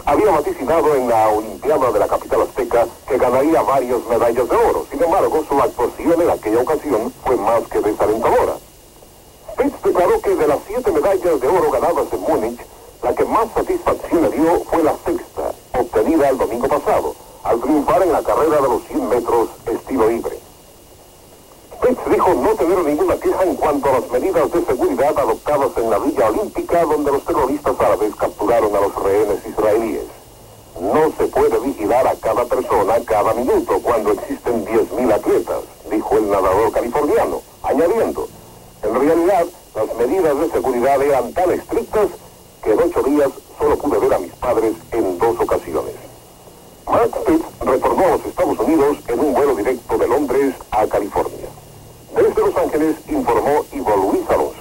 Crònica des dels Estats Units sobre les medalles aconseguides pel nedador nord-americà Mark Spitz, la seva opinió sobre la seguretat a la vila olímpica dels Jocs Olímpics de Múnic i el seu retorn als EE.UU.
Esportiu